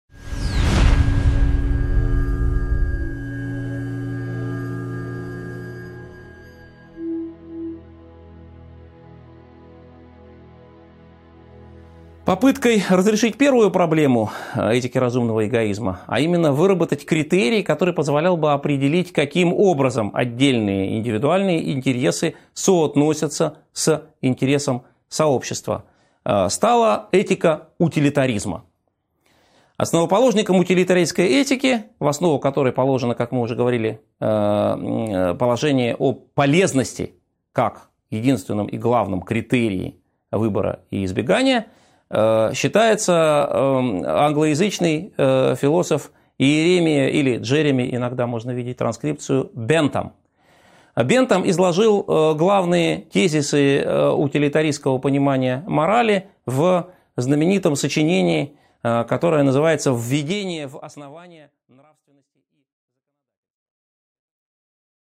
Аудиокнига 11.6 Этика утилитаризма | Библиотека аудиокниг